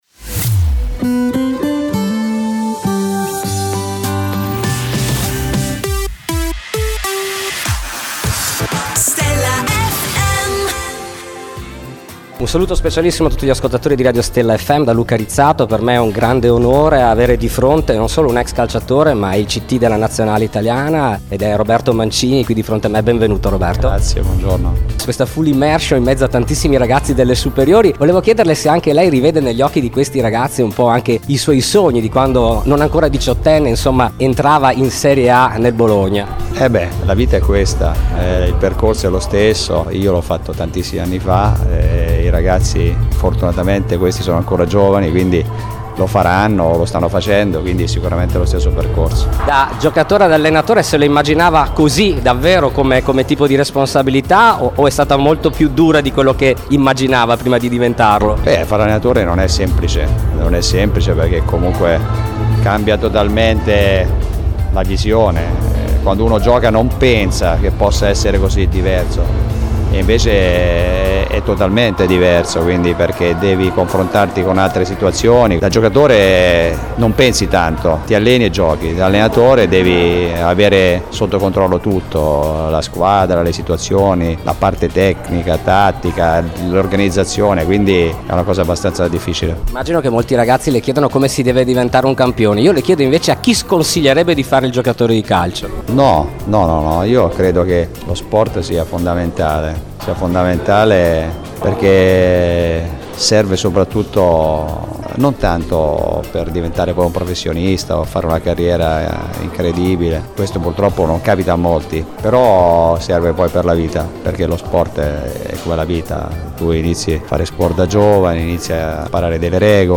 Intervista I Roberto Mancini | Stella FM
Intervista esclusiva dell’inviato per Stella FM a Roberto Mancini. .